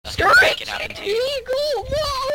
screeching eagle mode